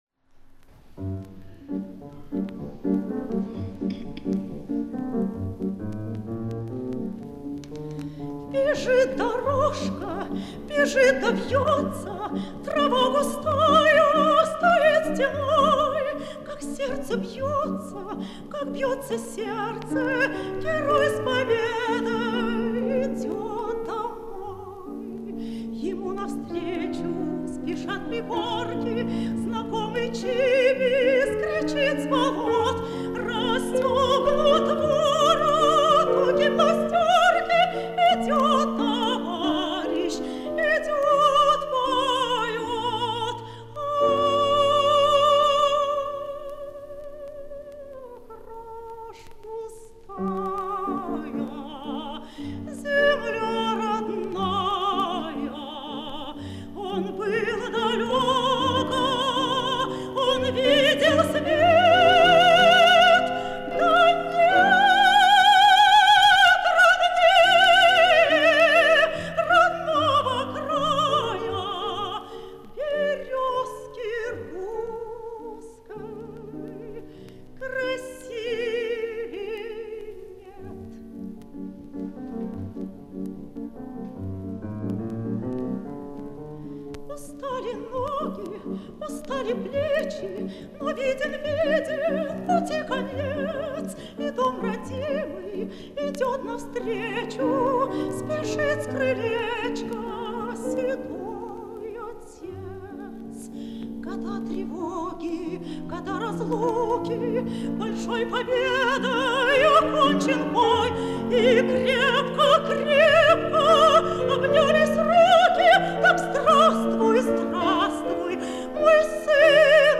Запись с авторского концерта
солистка театра им. Станиславского и Немировича-Данченко.
ф-но